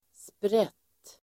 Uttal: [spret:]